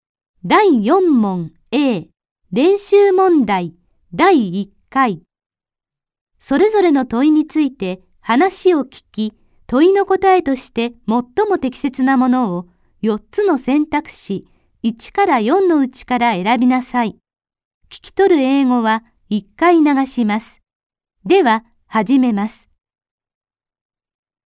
本試験に合わせ，一部イギリス英語も使用。